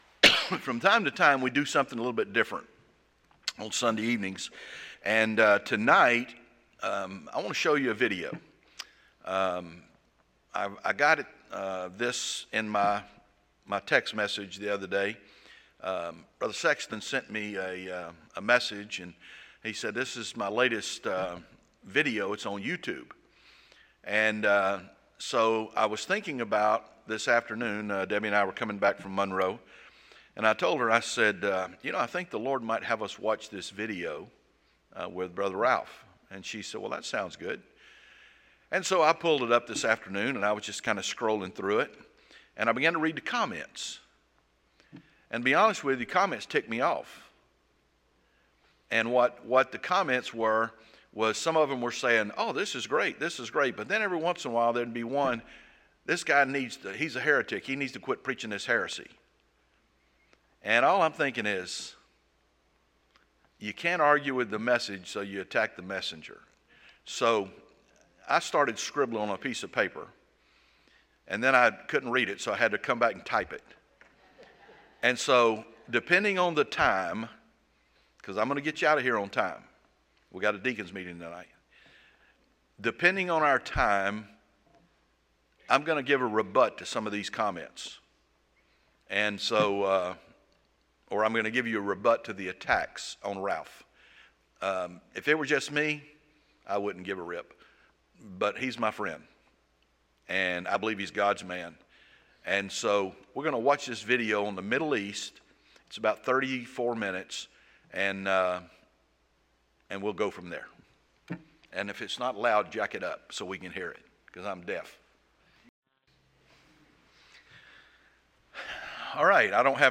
This Sunday Night service was a bit different.